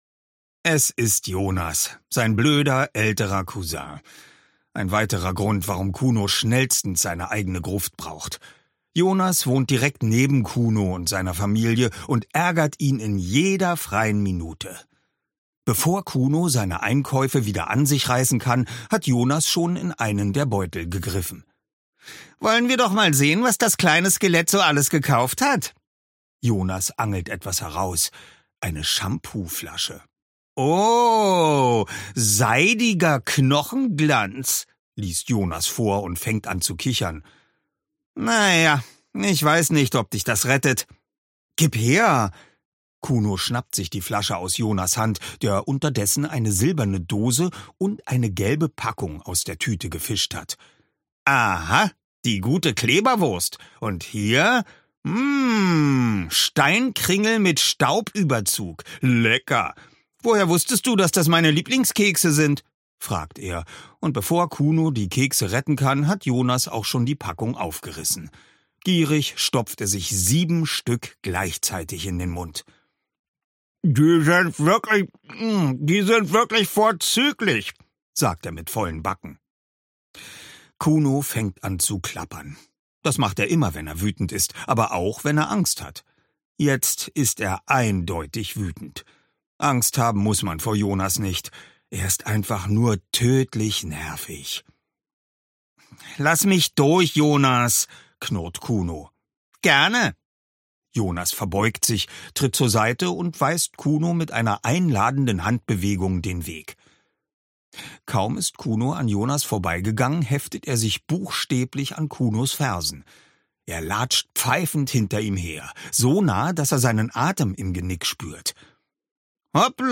Oliver Rohrbeck (Sprecher)
leicht gekürzte Lesung